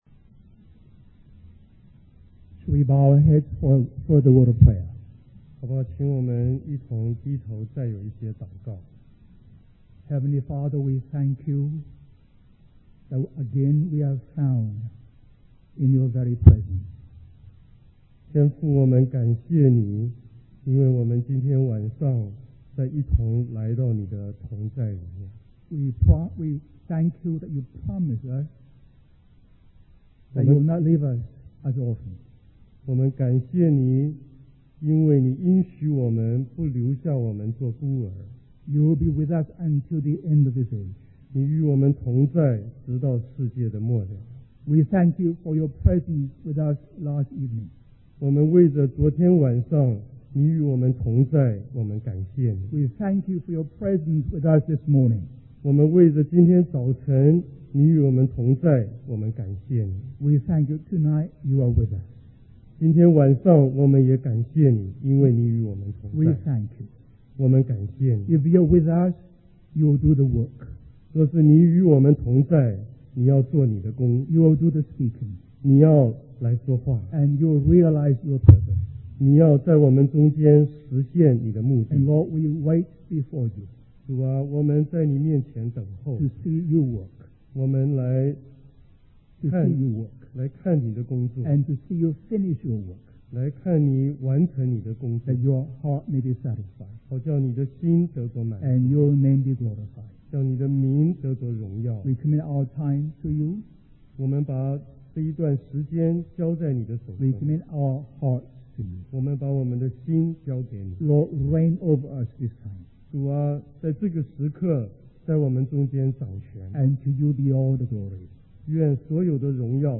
In this sermon, the speaker discusses the concept of the fullness of Christ and how no individual or group can fully express it. The speaker emphasizes the importance of knowing Jesus Christ, stating that it is the greatest miracle in the universe and brings life and everything.